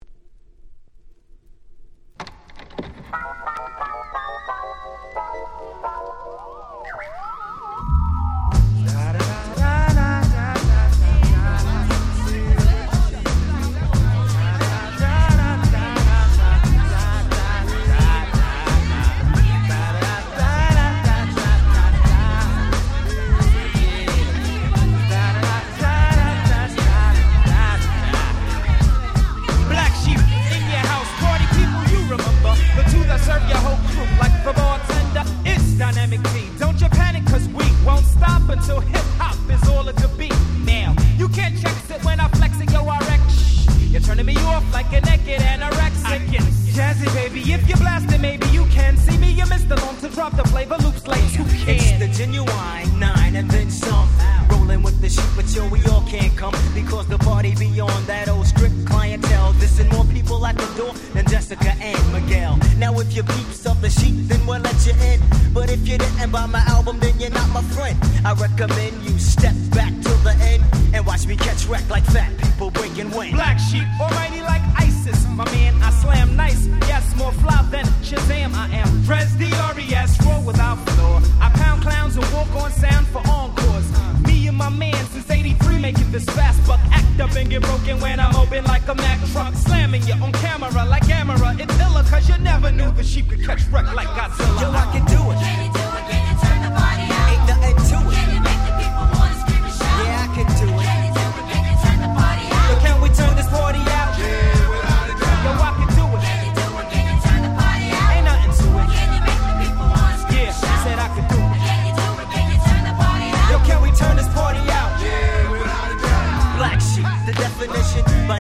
94' Smash Hit Hip Hop !!
Boom Bap